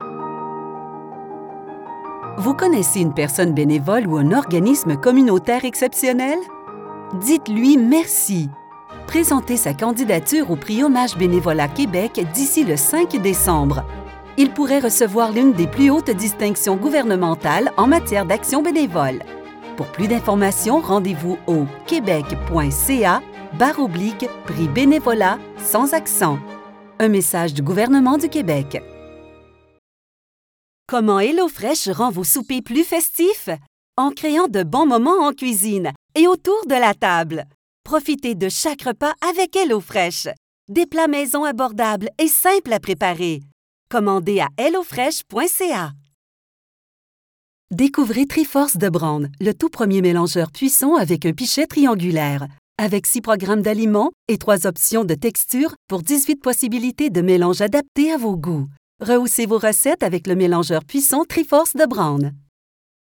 Accessible, Reliable, Corporate
Commercial